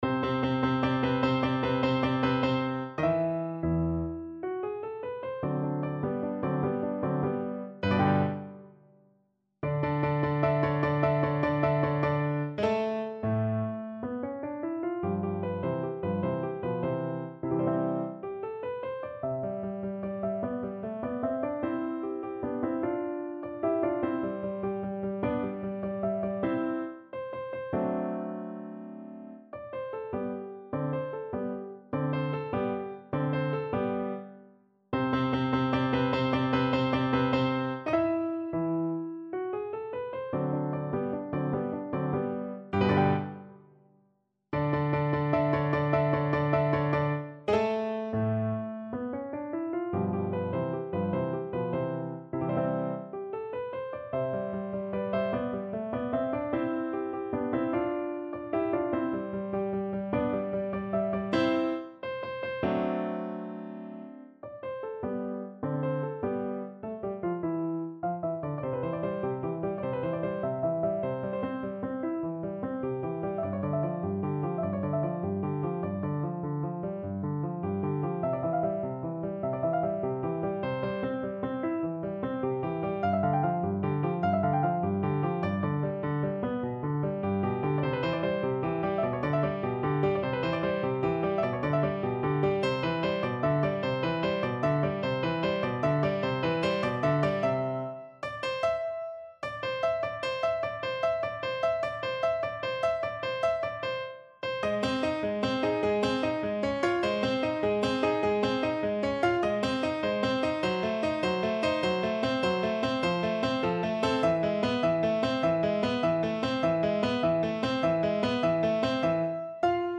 Piano version
No parts available for this pieces as it is for solo piano.
6/8 (View more 6/8 Music)
Allegro vivace . = c. 100 (View more music marked Allegro)
Piano  (View more Intermediate Piano Music)
Classical (View more Classical Piano Music)